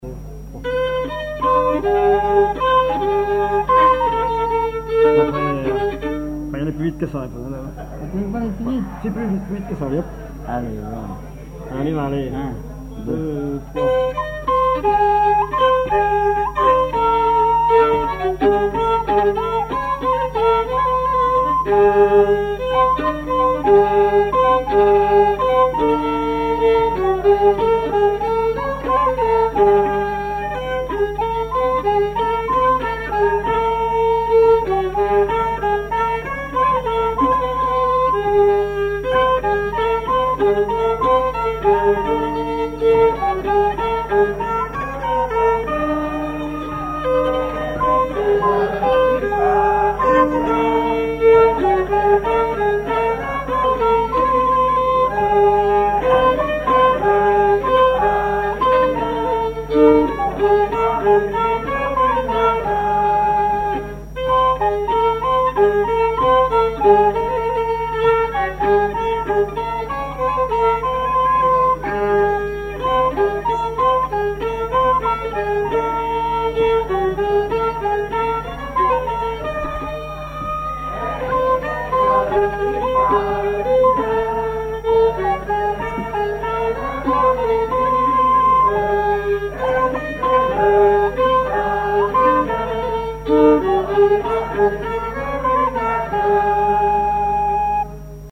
collectif de musiciens pour une animation à Sigournais
Pièce musicale inédite